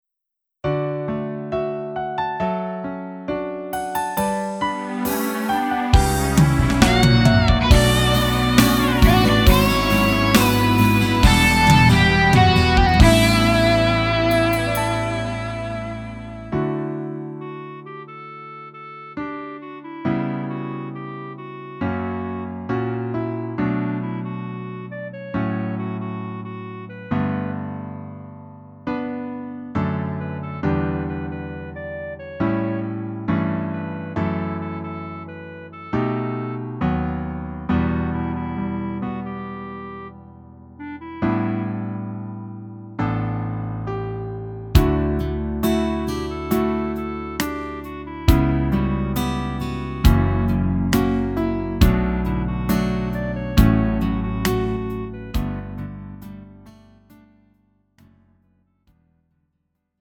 음정 -1키 3:53
장르 가요 구분 Lite MR